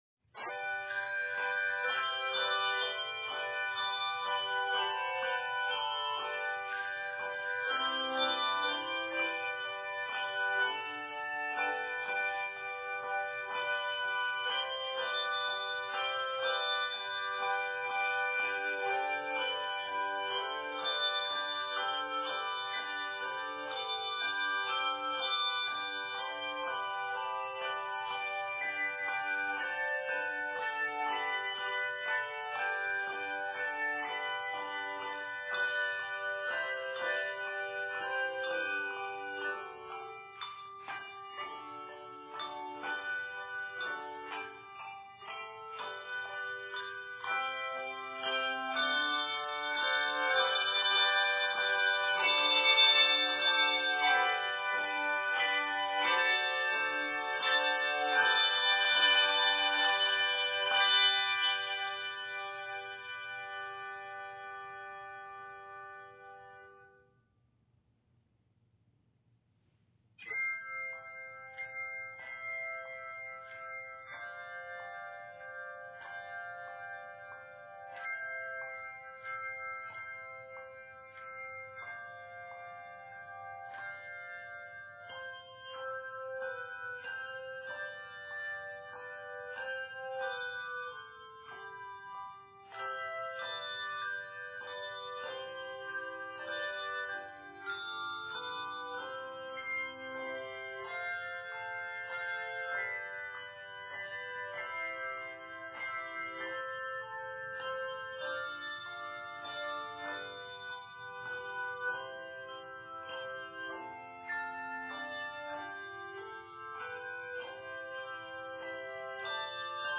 40 measures in G Major
A musical transition is provided when rung together.